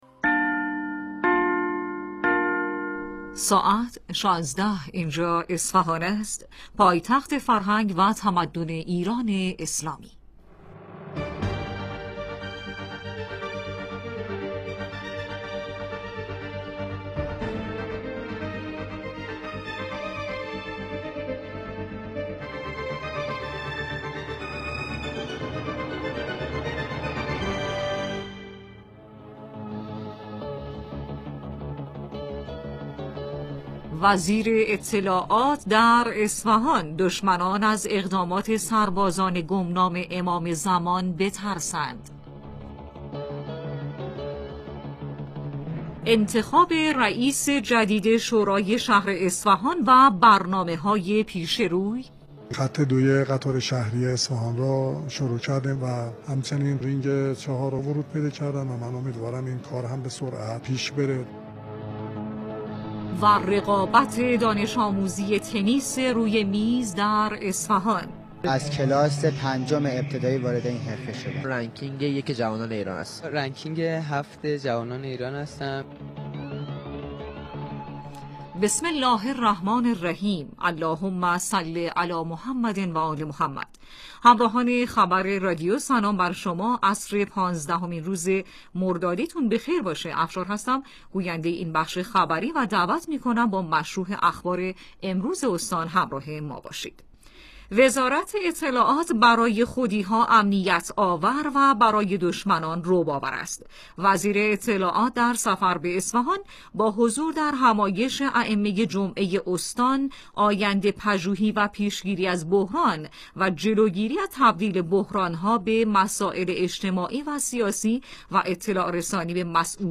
برخی از مهمترین رویدادها و گزارش های خبری امروز را در اخبار 16 صدای مرکز اصفهان بشنوید.